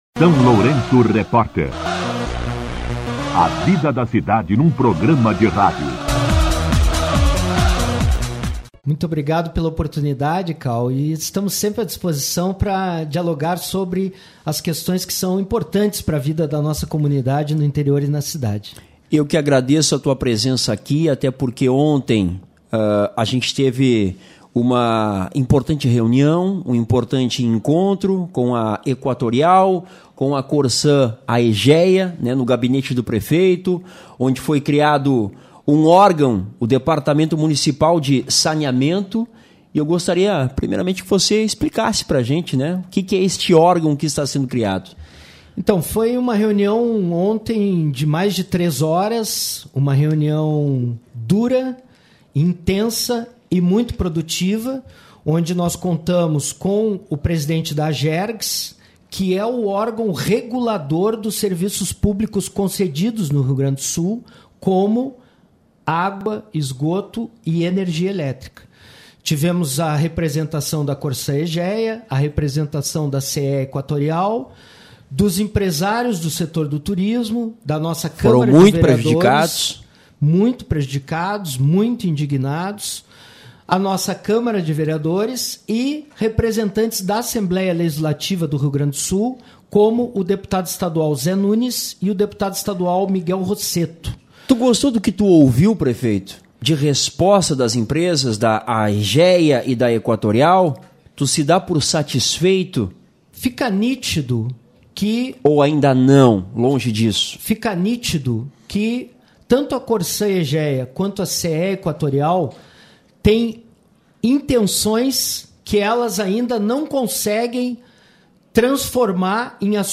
Entrevista com O prefeito Zelmute Marten
O prefeito Zelmute Marten esteve, ao longo da semana, na SLR Rádio para falar sobre a Reunião Interinstitucional sobre o Abastecimento de Água e a Fiscalização dos Serviços, realizada em seu gabinete com empresários do setor do turismo, além de representantes da Corsan/Aegea, Equatorial e Agergs.